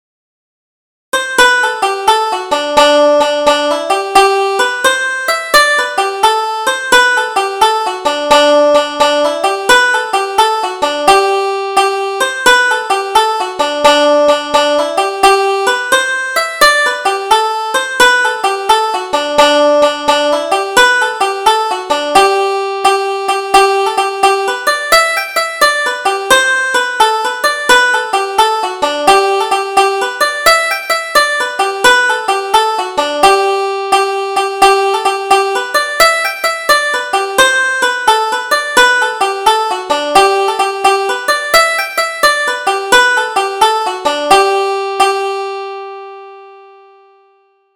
Double Jig: I'll Neither Spin nor Weave